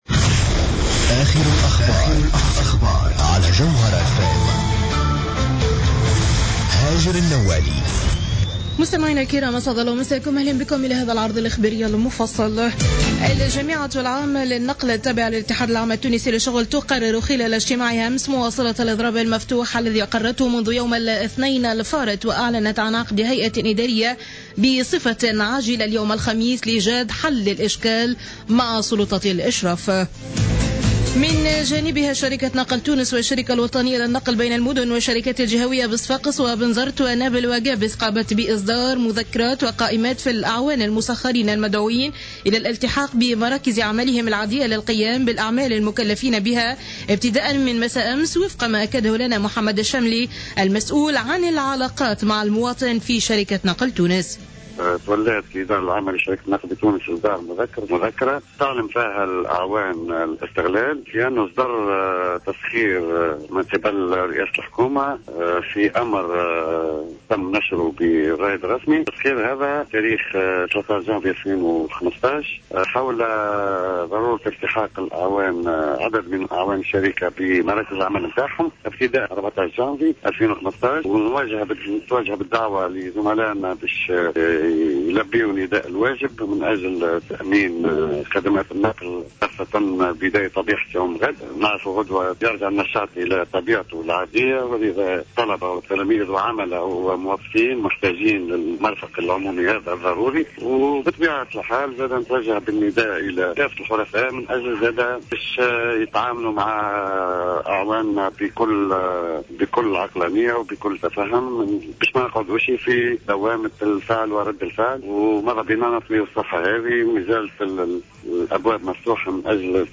نشرة أخبار السابعة صباحا ليوم الخميس 15 جانفي 2014